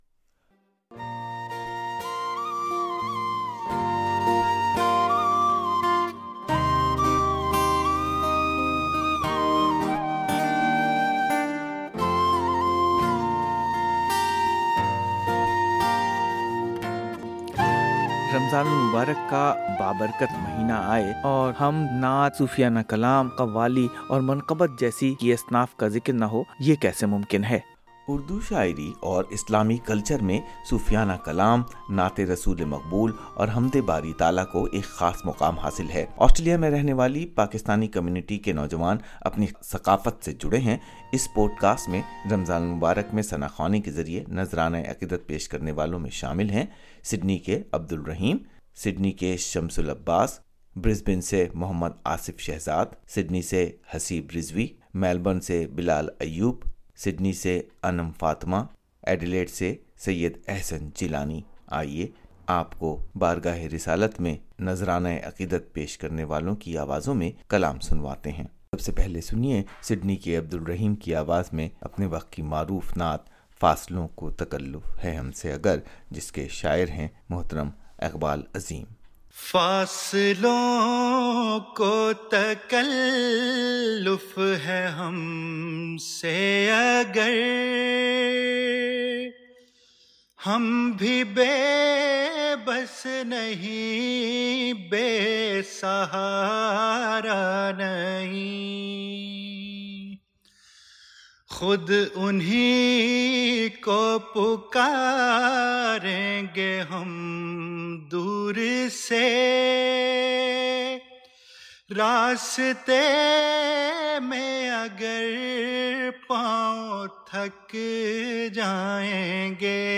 اس پوڈ کاسٹ میں نعتوں کے مختصر ورژن شامل ہیں۔
naat_podcast1.mp3